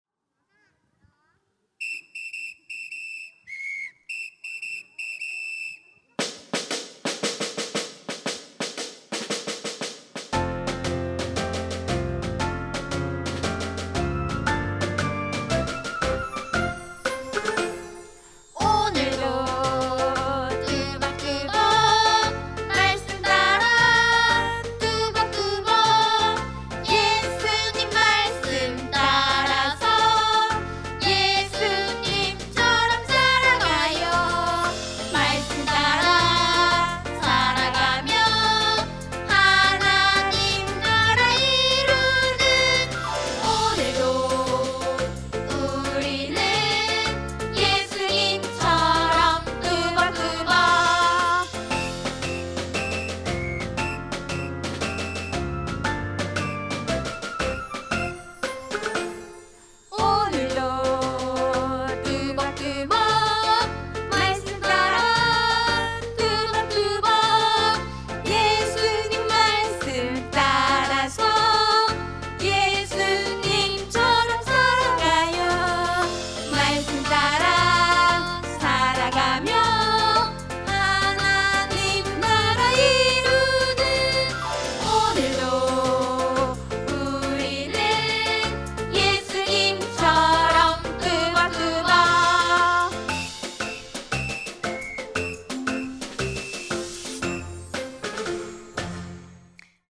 특송과 특주 - 오늘도 뚜벅뚜벅
유아부 어린이, 학부모, 교사